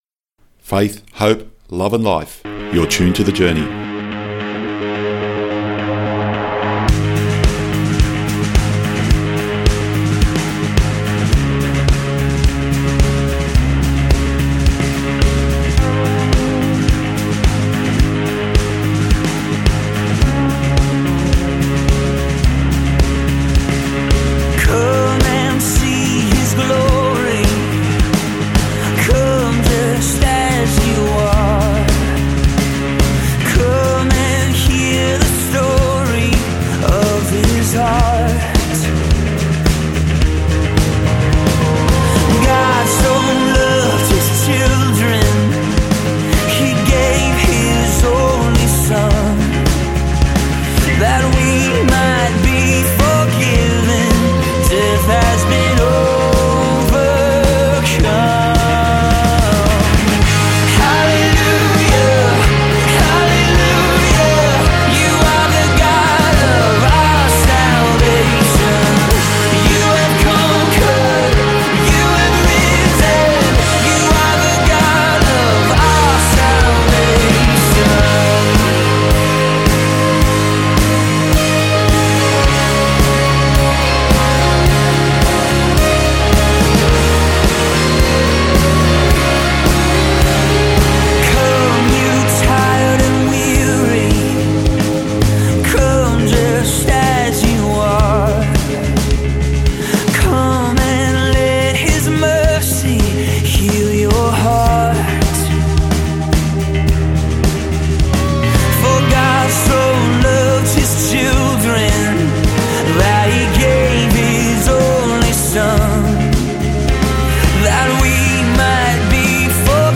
"The Journey" Catholic Radio is a weekly Christian Radio program produced by the Catholic Diocese of Wollongong and aired on Christian Radio Stations around the country.
There are also regular interviews highlighting interesting things being done by people in the Church.